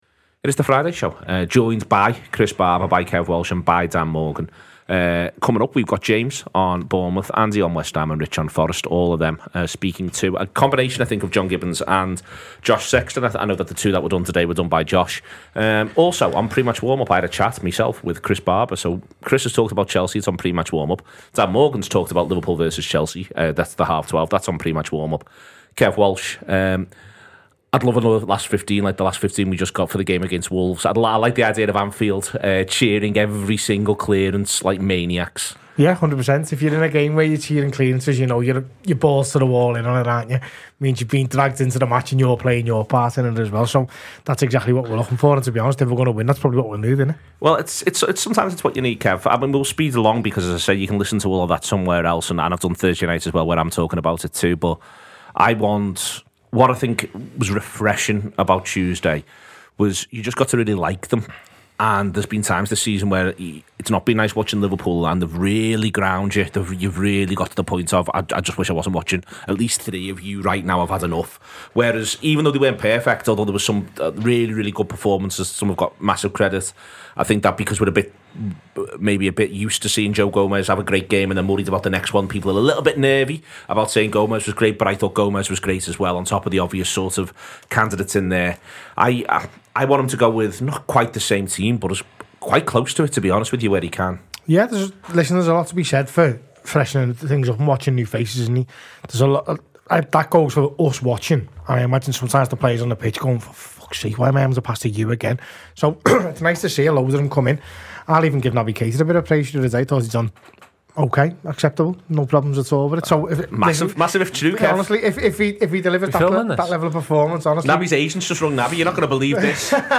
Fans preview the weekend’s Premier League action, with a number of the teams battling to steer clear of relegation facing off against each other.